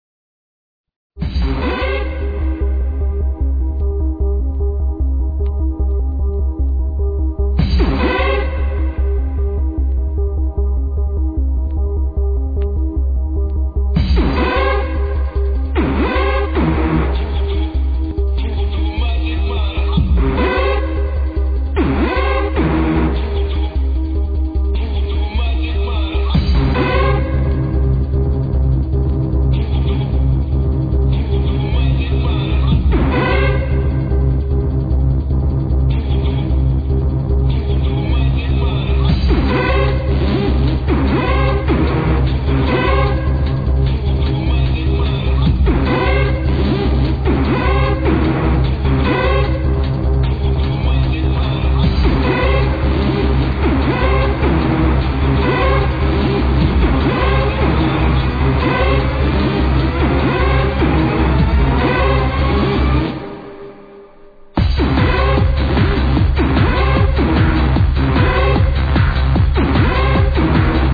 but anyways, this is the first song on the set,